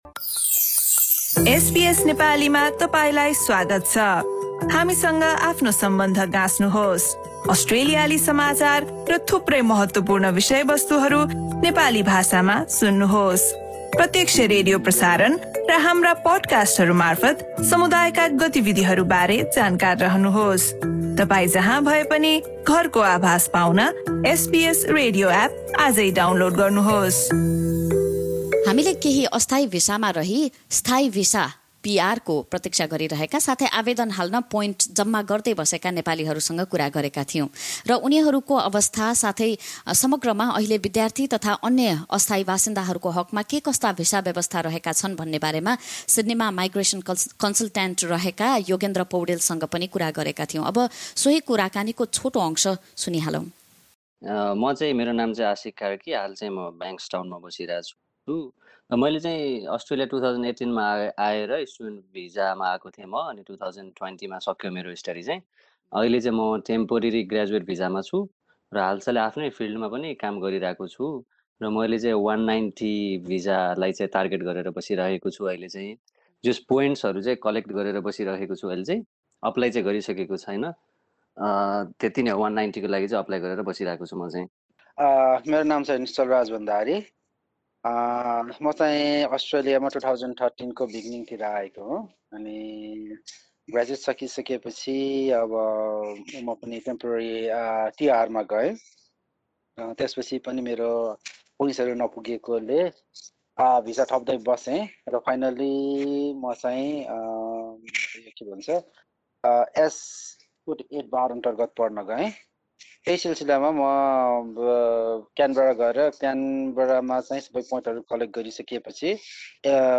कुराकानी